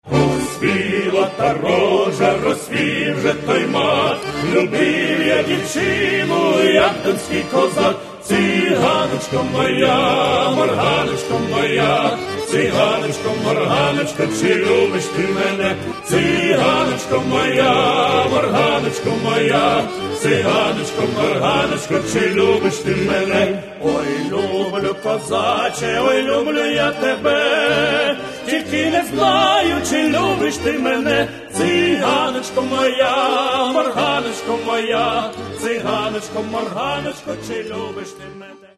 Народная